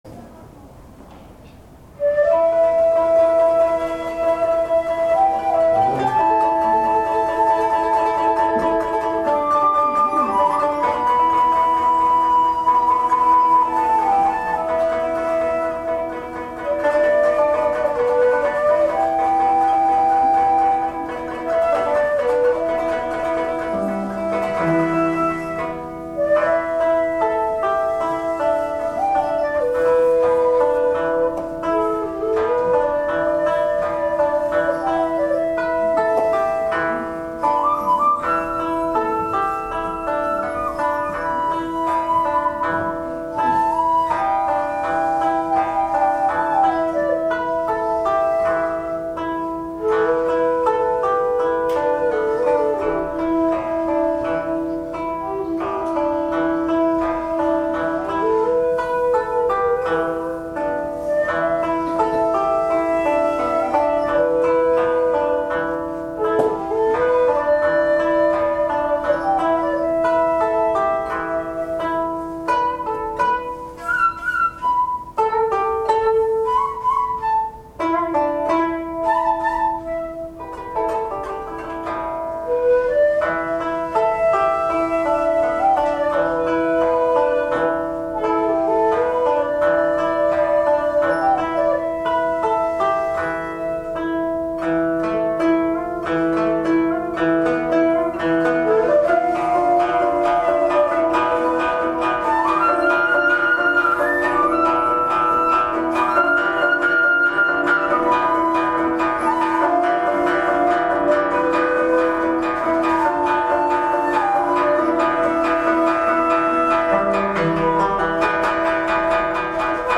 さて、今年一番の寒さの中、あまり暖かくない教室での演奏。